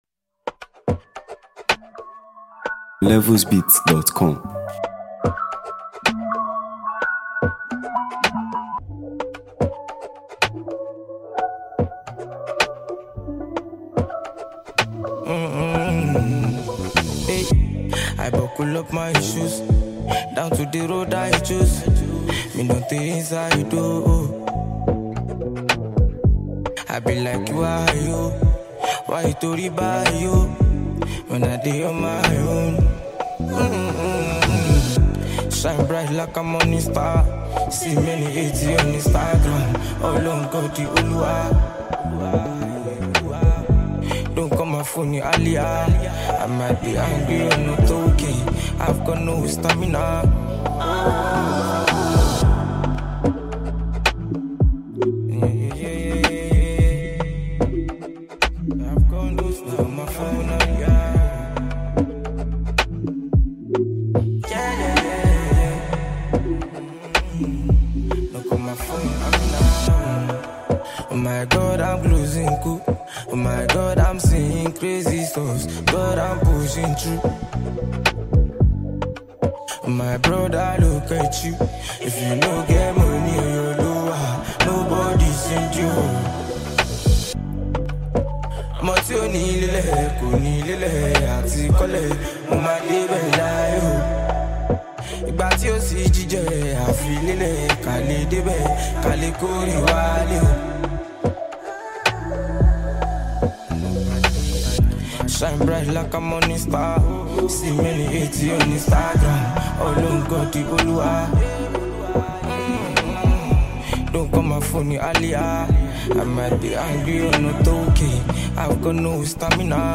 Nigerian singer
energetic track
For lovers of fresh Afrobeat and chart-topping vibes